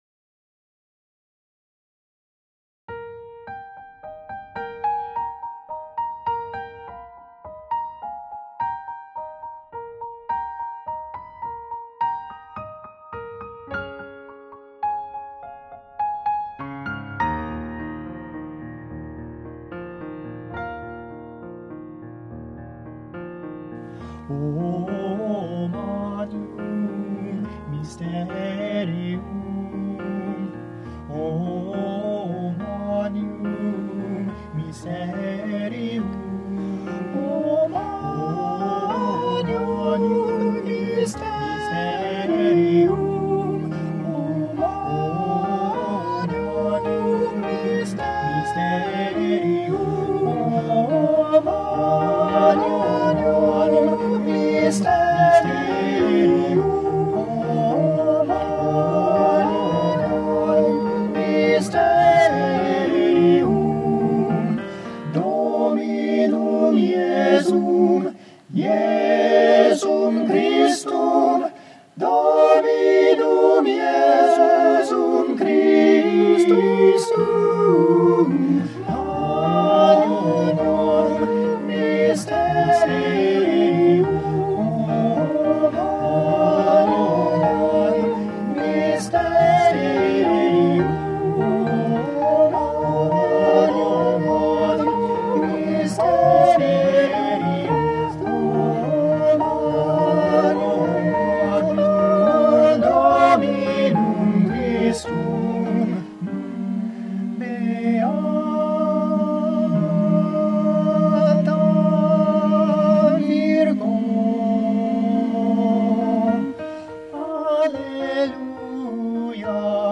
Mercy" (SAB, Piano)
Commissioned for First Baptist Church Madison choir (2019)